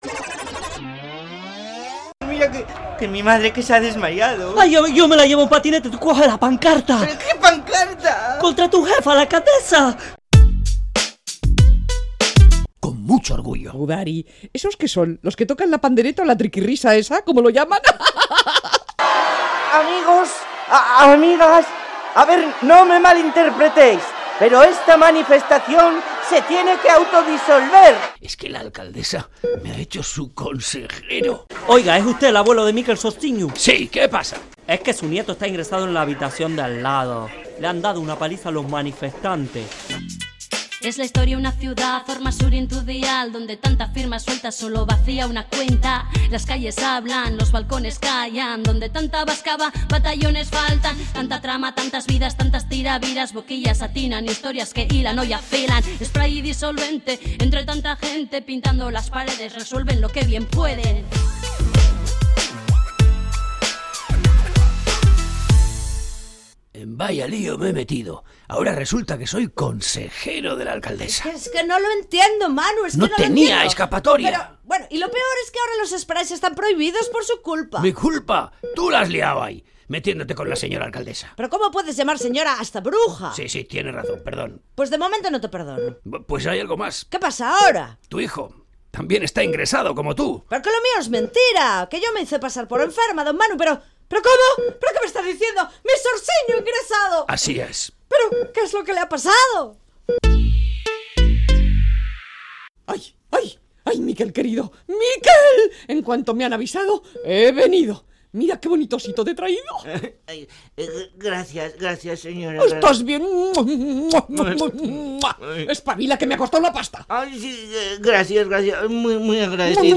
Entrega número 14 de la Radio-Ficción “Spray & Disolvente”